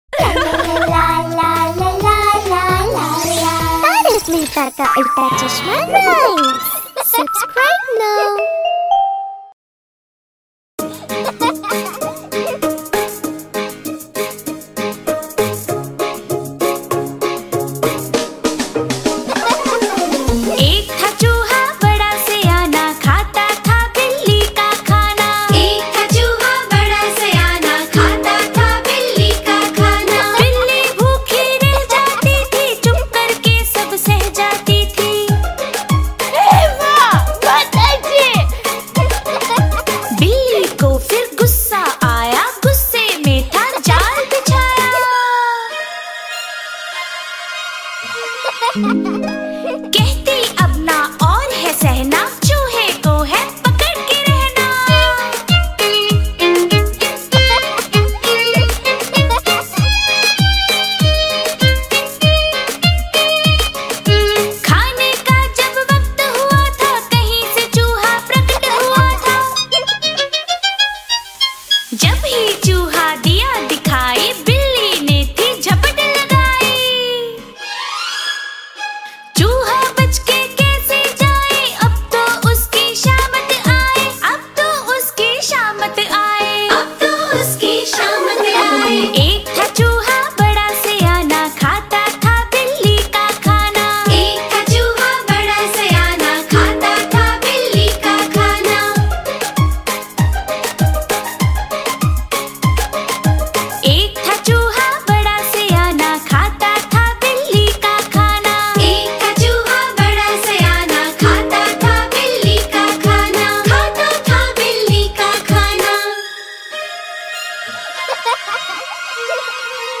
Hindi Nursery Rhymes for Children